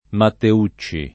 Matteucci [ matte 2©© i ]